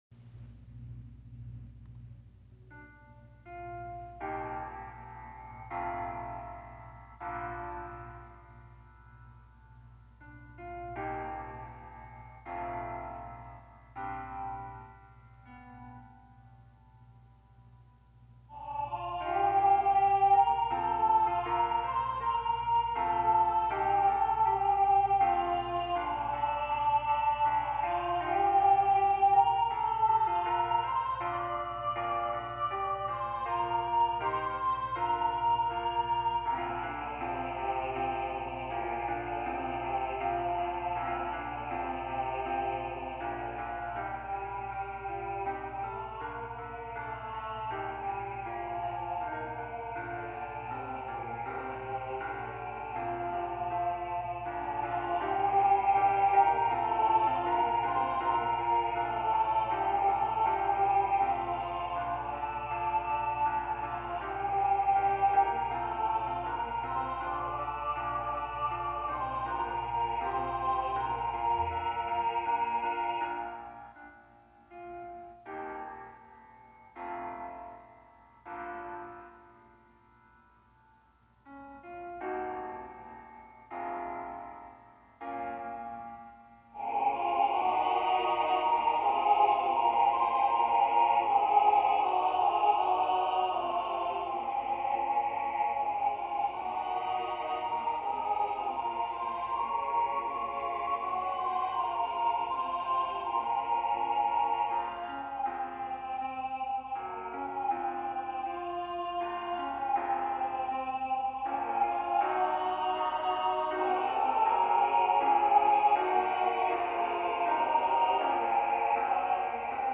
SATB, SSATB